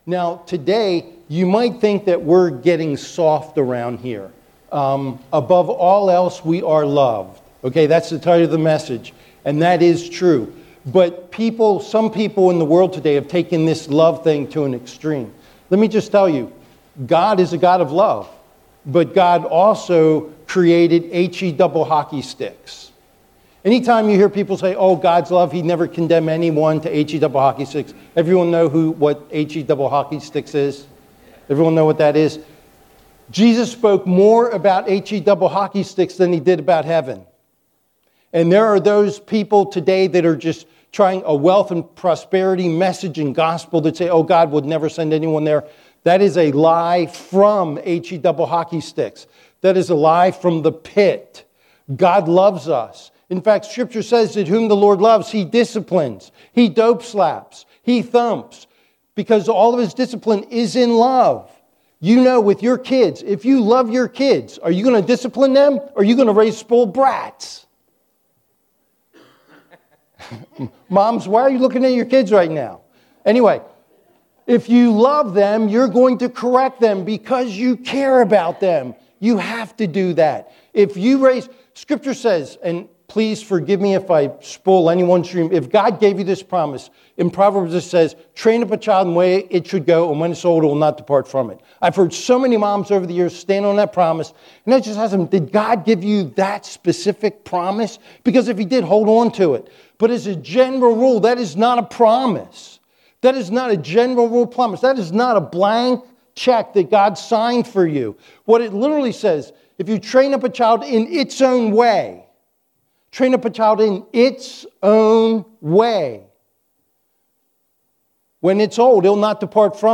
Sermons | Buckhannon Alliance Church